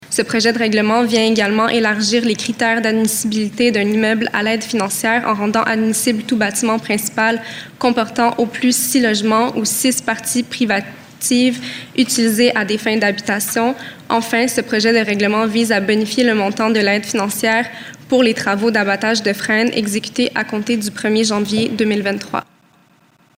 De plus, il offre à un plus grand nombre de propriétaires la possibilité d’obtenir une aide financière, comme le dit la conseillère Lisa Bélaïcha.